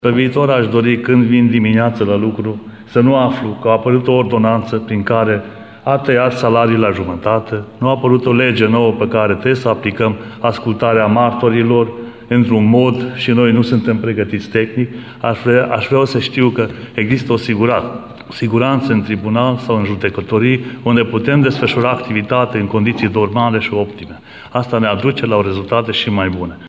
presedinte-tribunalul-arad.mp3